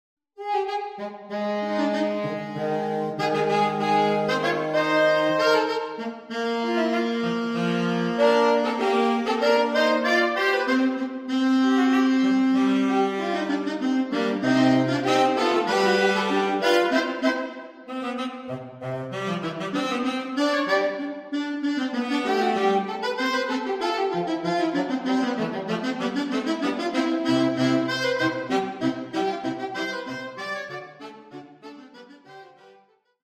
Saxophone Trio
Traditional Sea Shanties
3. Hornpipe